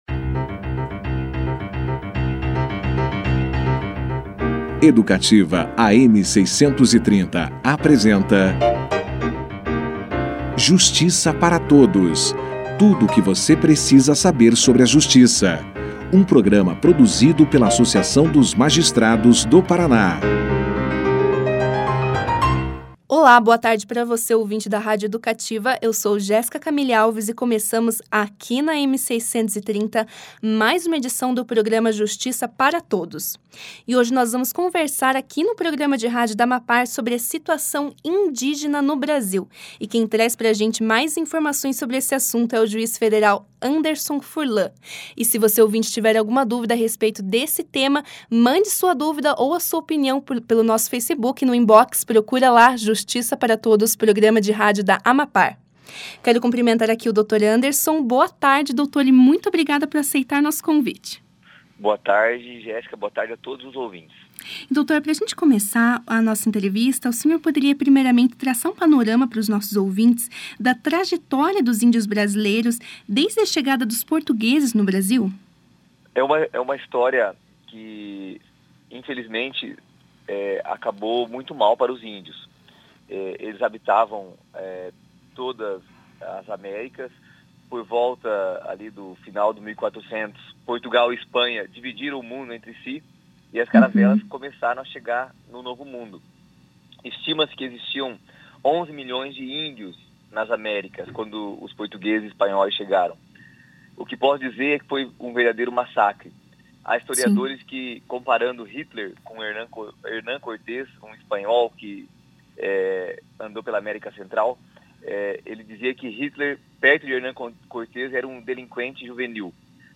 Confira na íntegra a entrevista feita com o juiz federal Anderson Furlan